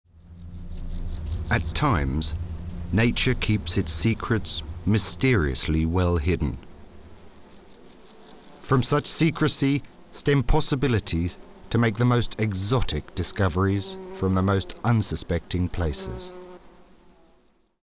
Sprecher englisch uk.
Sprechprobe: Werbung (Muttersprache):